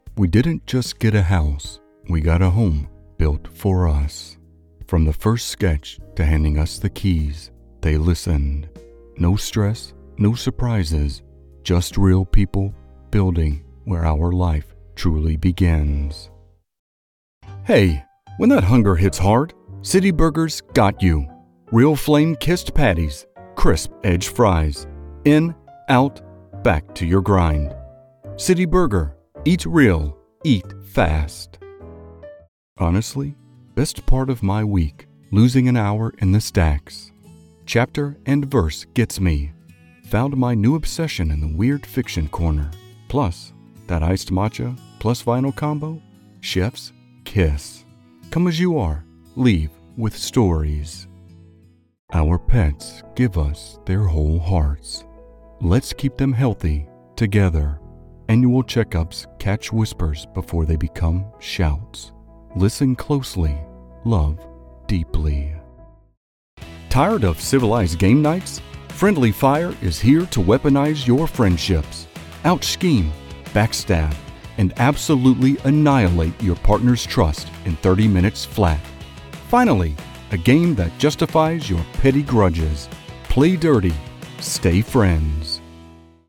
male voiceover artist with a rich, deep, and exceptionally smooth vocal tone.
Diverse Demo
General American, Southern
Voice Demo - Diverse.mp3